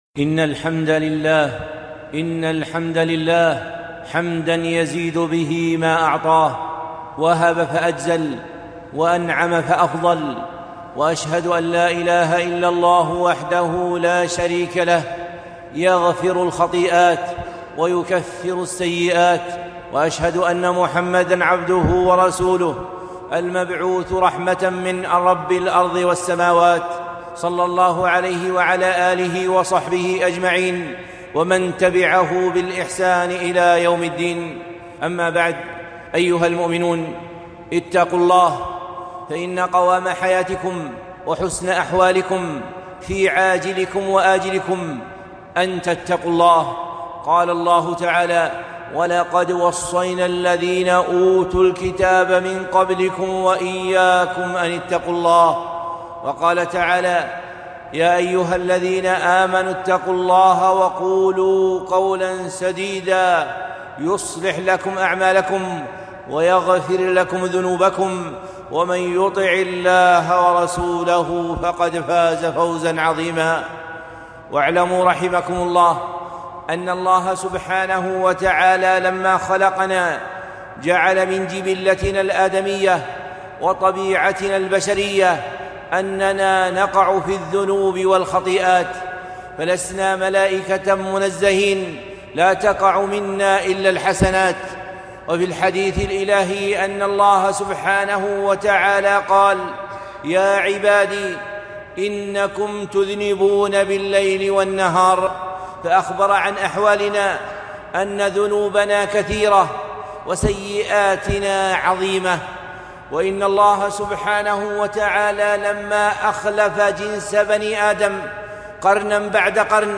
خطبة قيمة - ذنوب تدور ورب غفور